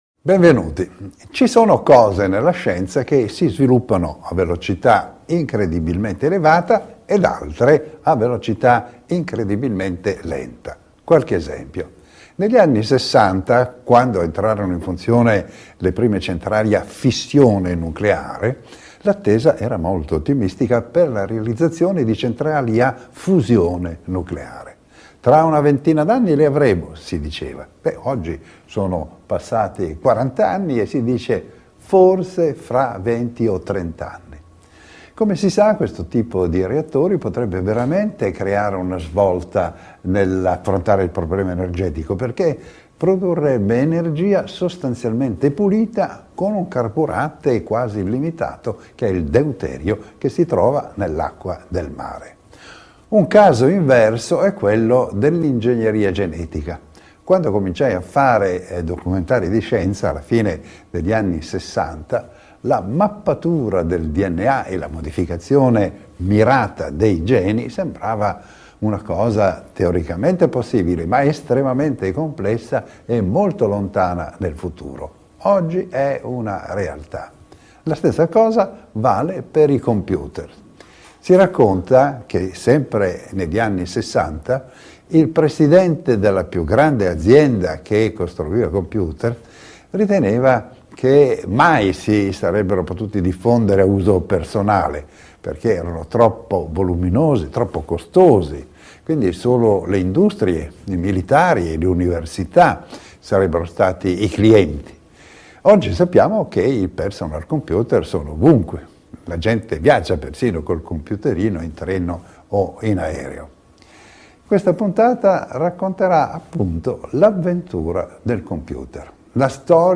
narrator-david-attenburo - David Attenborough narrates your life